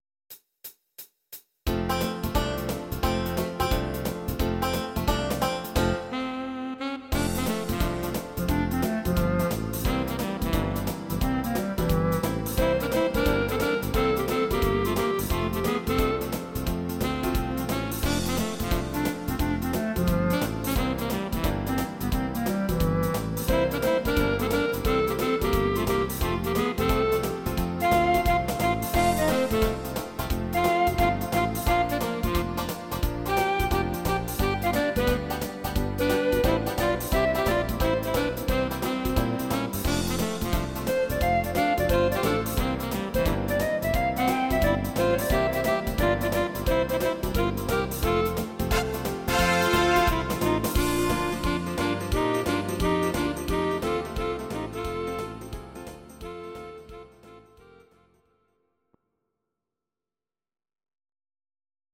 These are MP3 versions of our MIDI file catalogue.
Please note: no vocals and no karaoke included.
instr. Saxophon & Klarinette